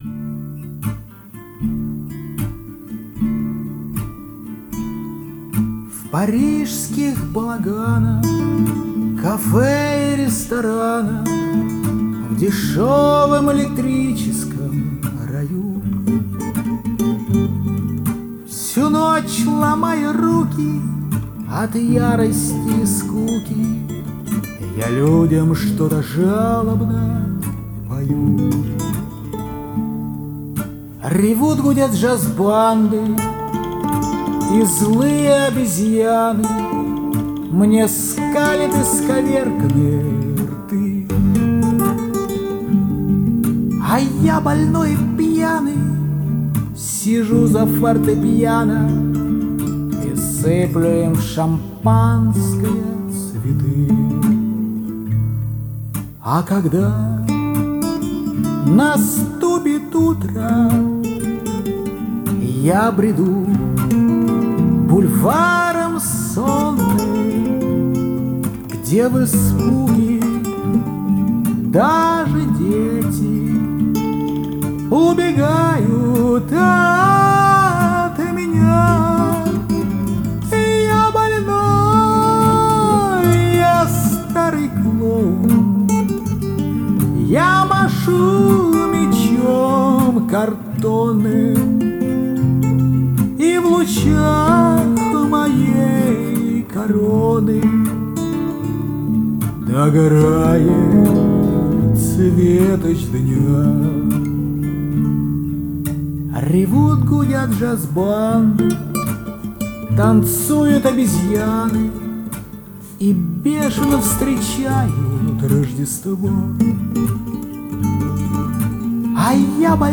Дуэт двух мульти-инструменталистов
Жанр: Фолк, Шансон, Романс, Акустика